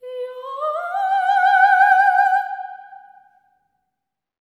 LEGATO 01 -L.wav